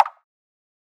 Perc (BigTime).wav